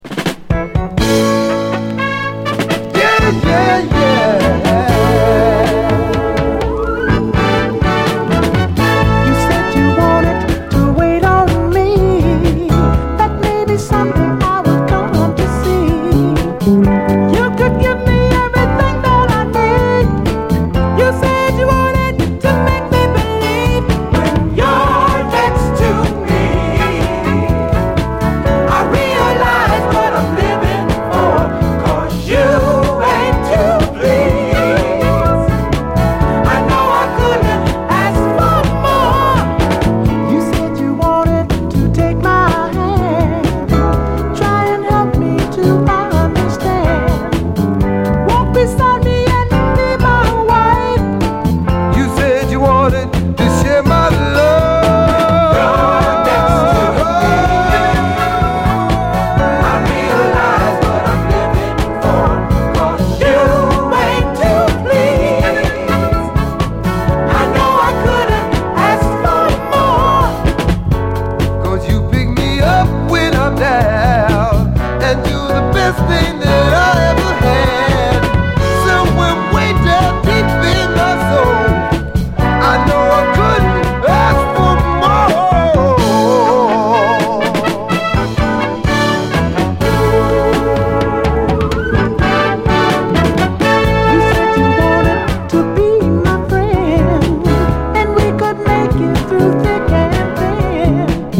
エレピとスペイシーなシンセが絡み付く